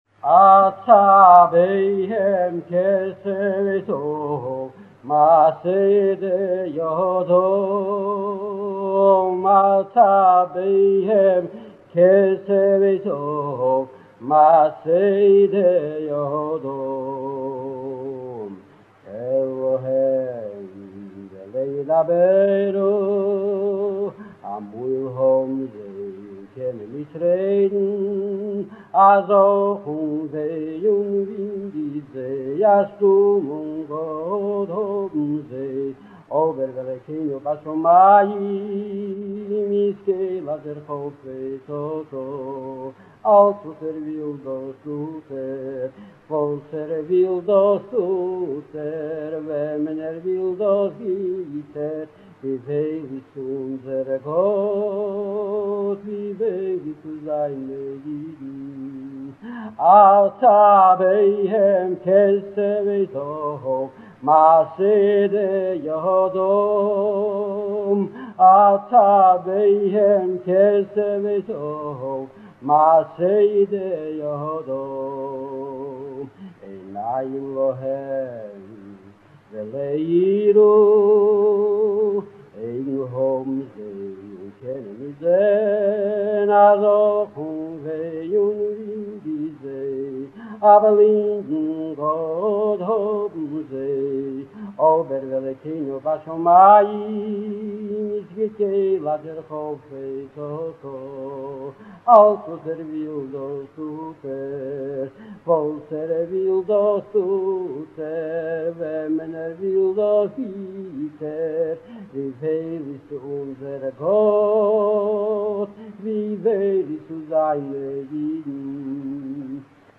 על פי הנוסחה השניה שבניגון, כל פיסקה מצטרפת לקודמותיה באופן דומה לניגונים המסורתיים של חג הפסח חד גדיא ואחד מי יודע (בנוסחה זו, חלק מהניגון דומה בתנועותיו לניגון האחים מסלאוויטא).